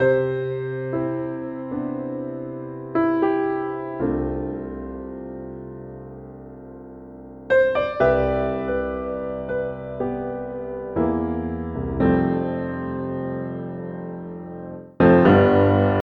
Piano Ringtone From A Sad Or Slow Country Song.